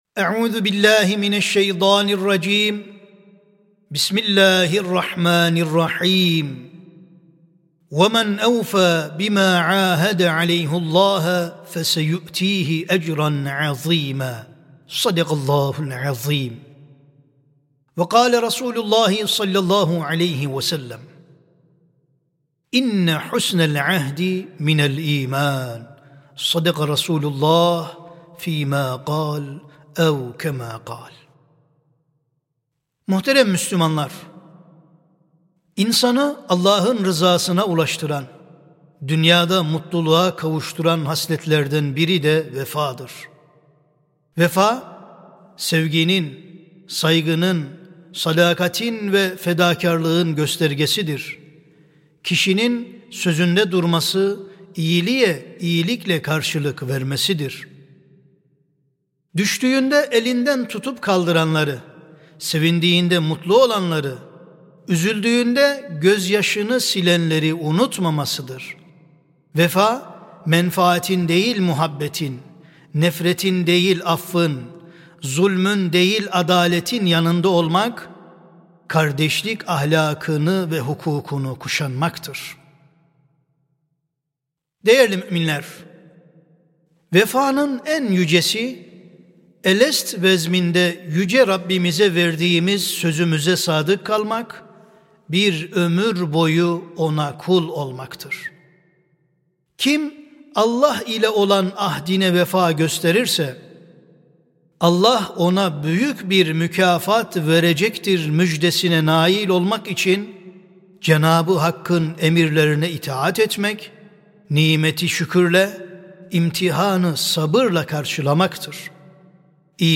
Sesli Hutbe (Vefa İmandandır).mp3